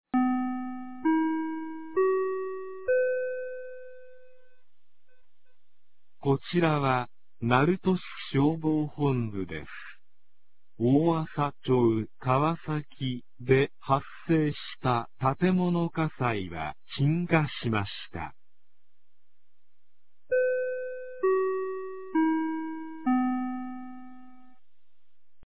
2025年11月17日 18時45分に、鳴門市より大麻町-川崎、大麻町-津慈、大麻町-板東、大麻町-桧へ放送がありました。